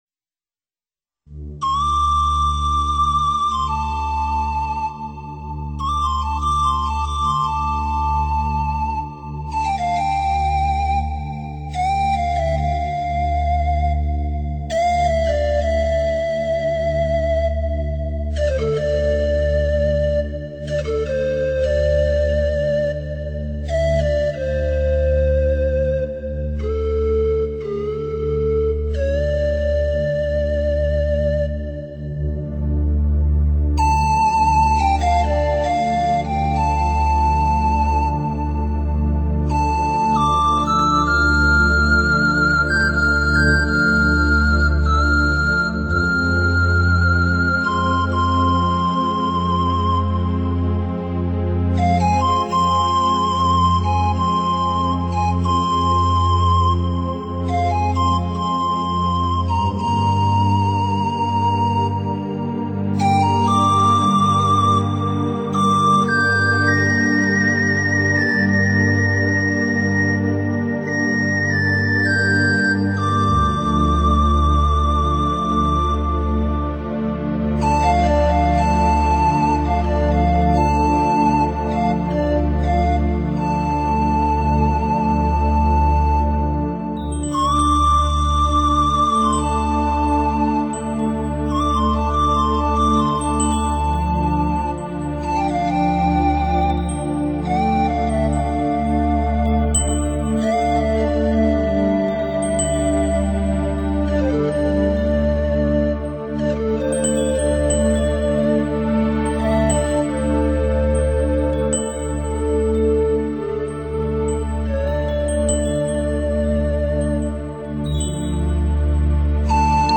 当风声中的乐音响起，从宽阔的音场中即可领略到一种速度临场感